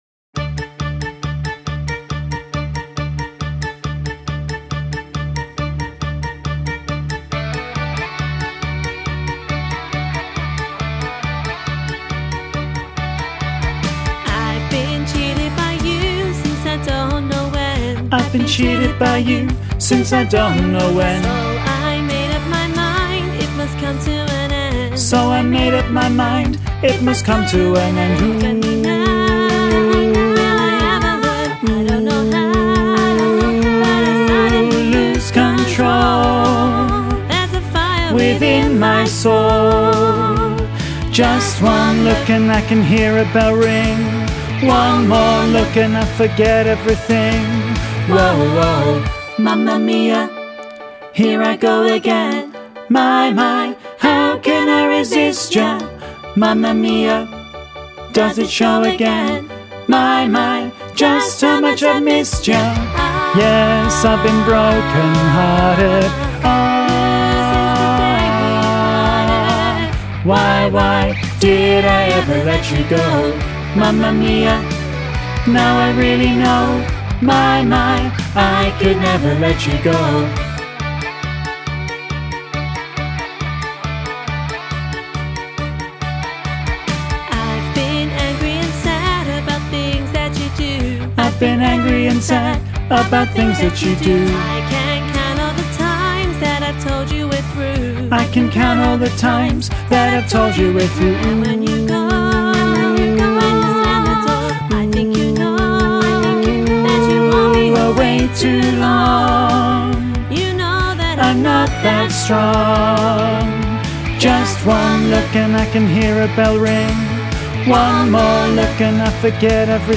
Training Tracks for Mamma Mia
Listen to bass track with soprano and alto accompaniment
mamma-mia-bass-half-mix.mp3